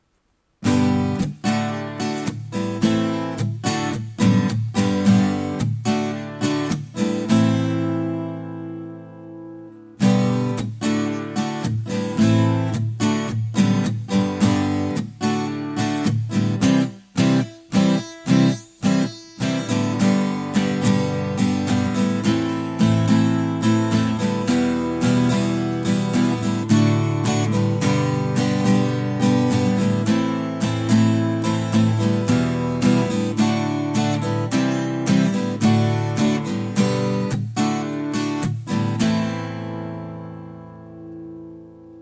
That's just a short clip of me and getting the acoustic guitar - two mic recording w some reverb etc Attachments Mic Test.mp3 Mic Test.mp3 657.8 KB · Views: 147